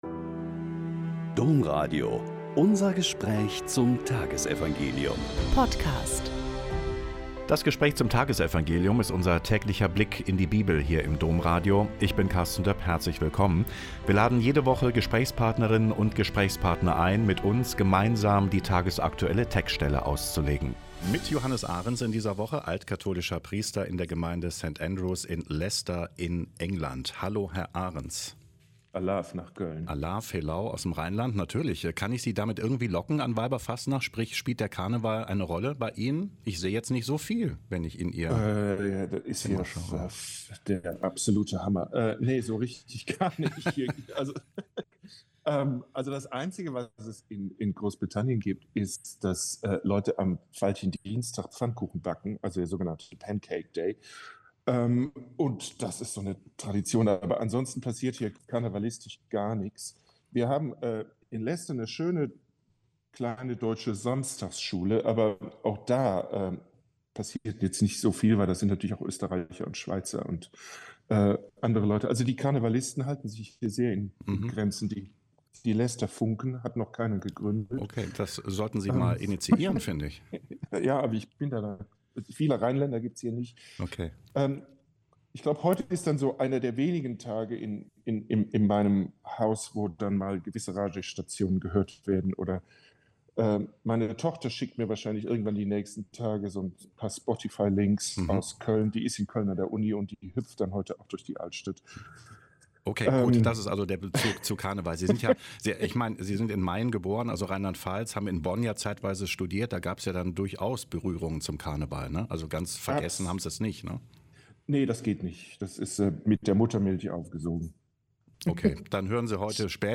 Mk 9,41-50 - Gespräch